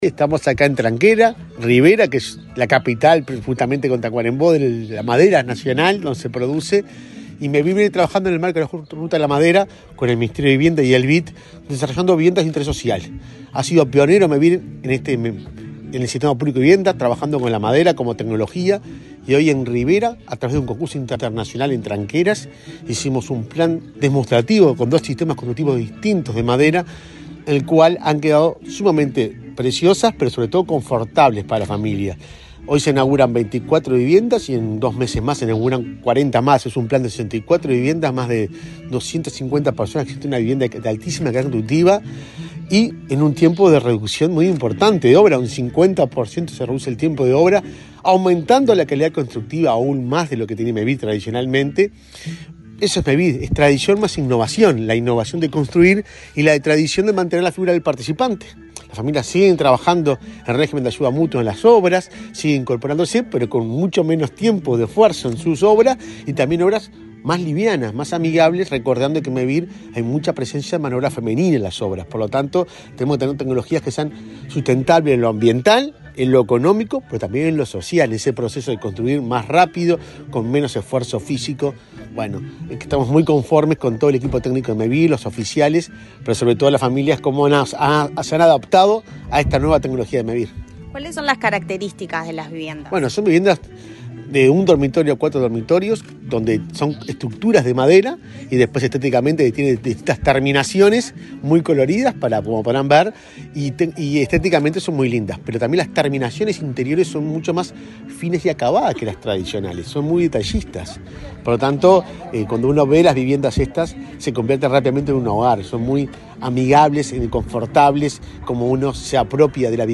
Entrevista al presidente de Mevir, Juan Pablo Delgado
El presidente de Mevir, Juan Pablo Delgado, dialogó con Comunicación Presidencial en la localidad de Tranqueras, departamento de Rivera, donde ese